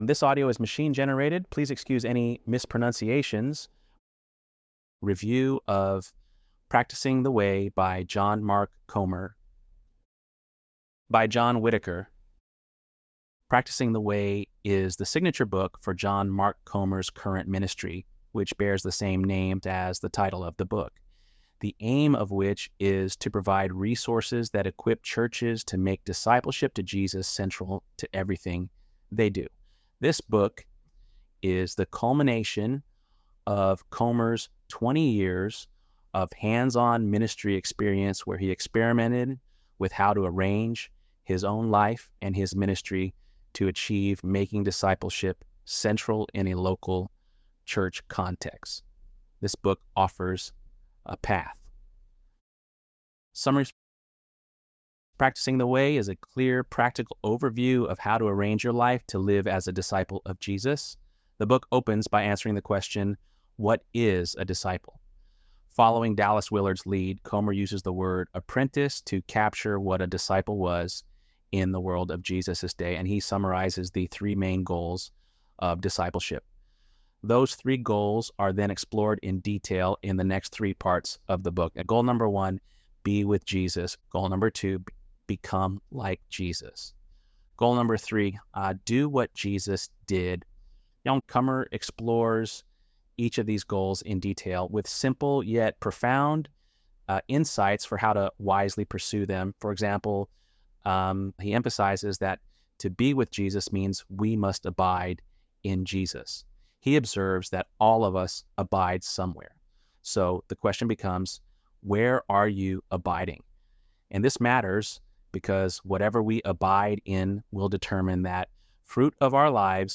ElevenLabs_10.4.mp3